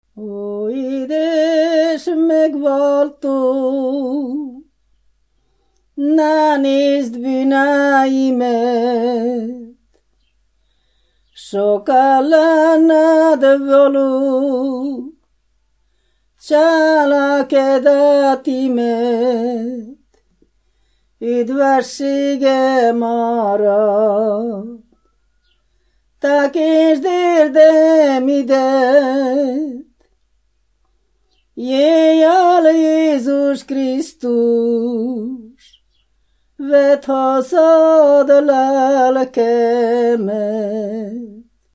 Moldva és Bukovina - Moldva - Szitás
ének
Műfaj: Népének
Stílus: 7. Régies kisambitusú dallamok
Kadencia: 3 (2) 1 1